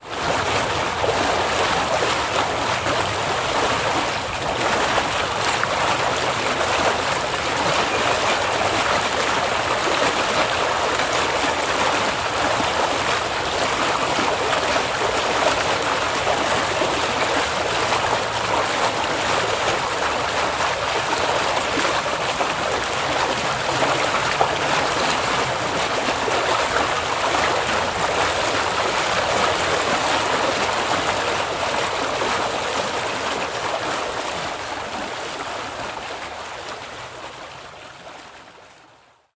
描述：A broken water main behind the studio.
标签： rushing river pipe water
声道立体声